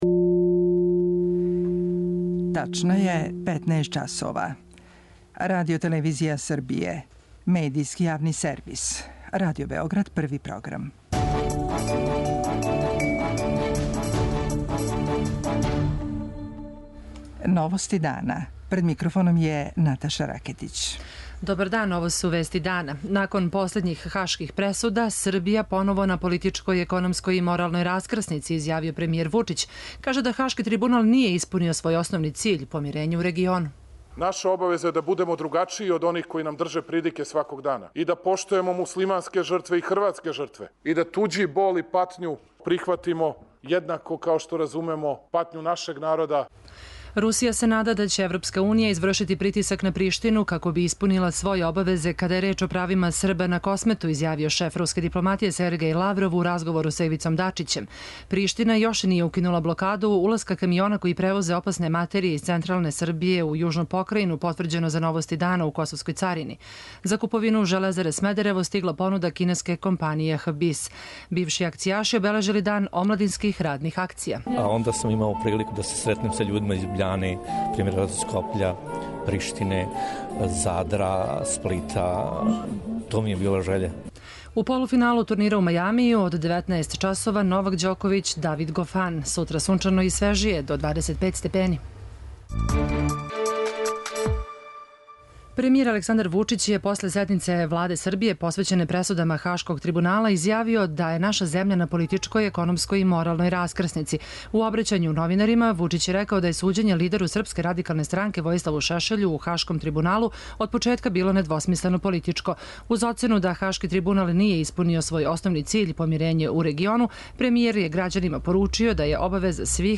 Премијер Александар Вучић изјавио је, после седнице Владе посвећене пресудама Хашког трибунала, да је Србија на политичкој, економској и моралној раскрсници. У обраћању новинарима, Вучић је рекао да је поступак лидеру Српске радикалне странке Војиславу Шешељу у Хашком трибуналу од почетка био недвосмислено политички.